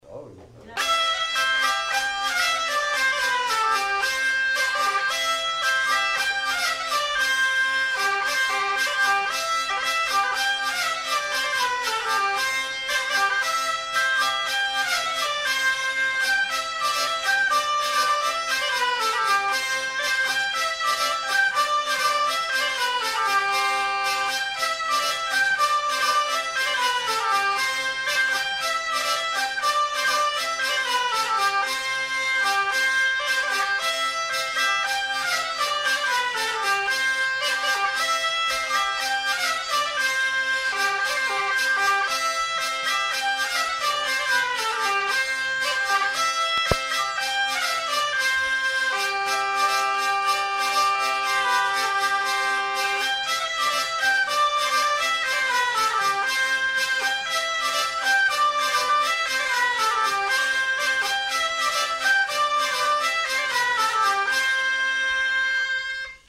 Lieu : Pyrénées-Atlantiques
Genre : morceau instrumental
Instrument de musique : vielle à roue ; cabrette
Danse : polka